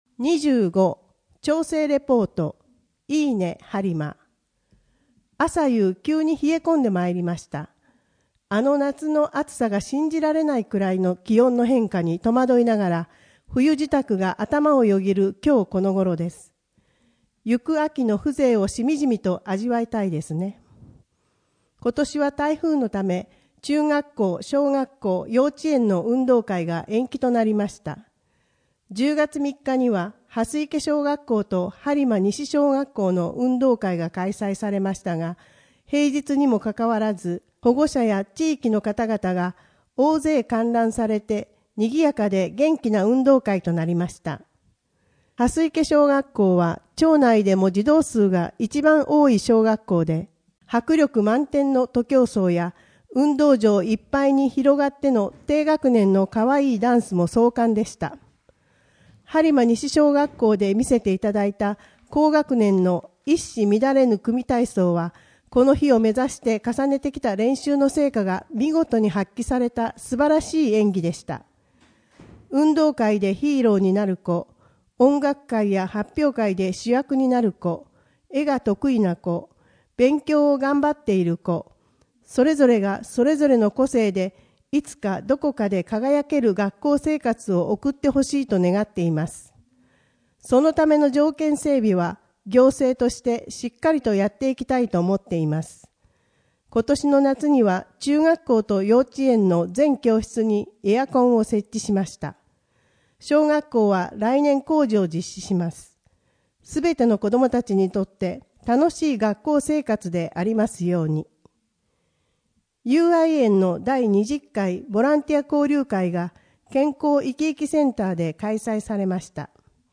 声の「広報はりま」11月号
声の「広報はりま」はボランティアグループ「のぎく」のご協力により作成されています。